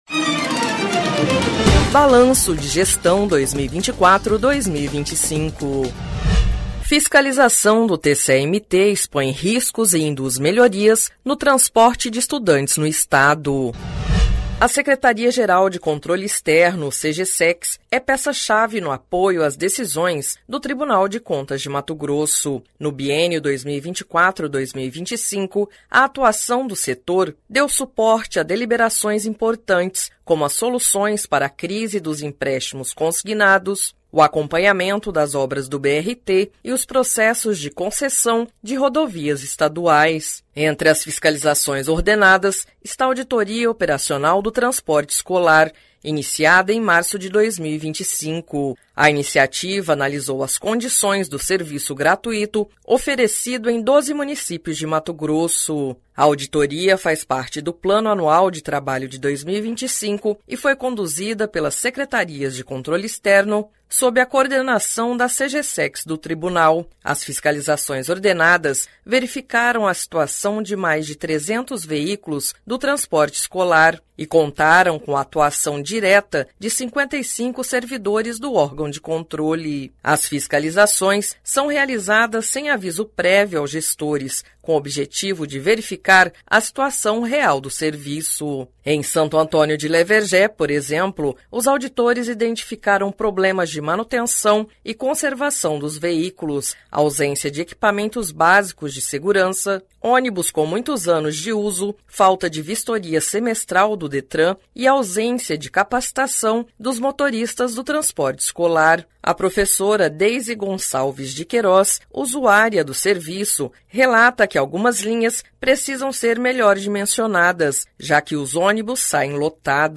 Sonora: Adelmar Gallio - secretário de Educação, Esporte e Lazer de Santo Antônio de Leverger